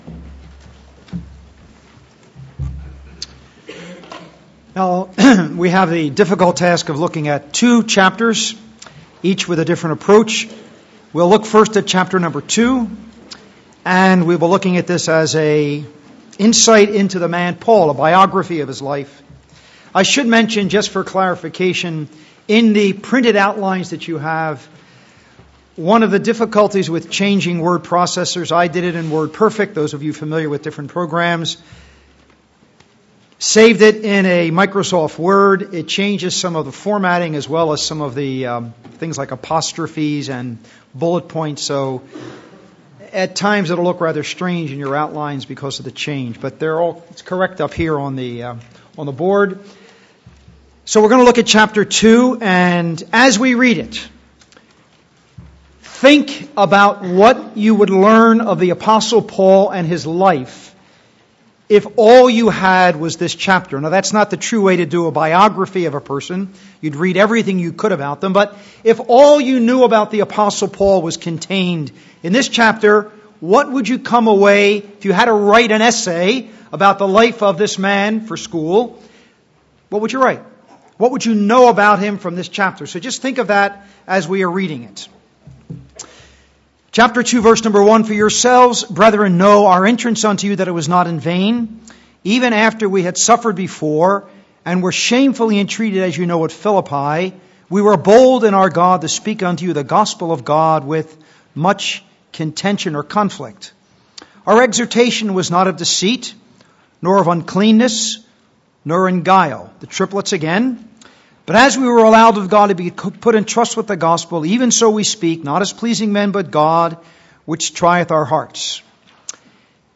Series: How to Study Your Bible Service Type: Ministry